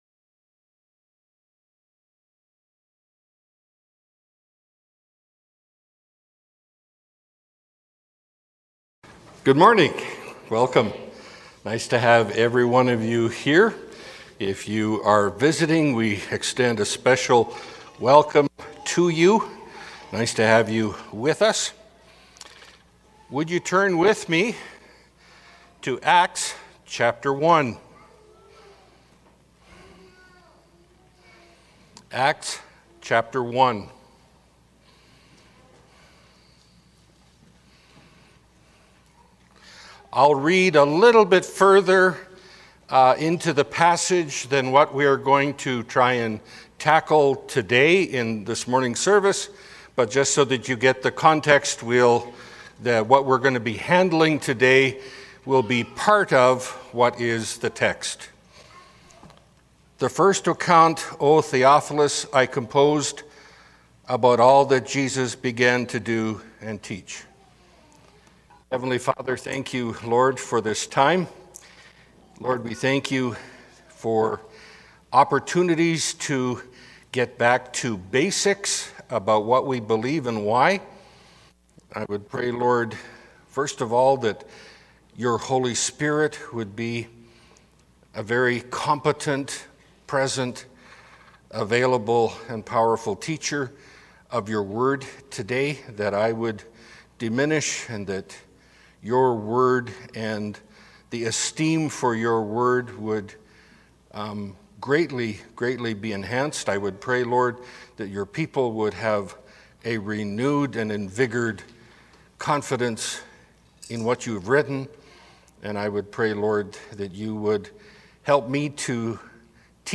Pulpit Sermons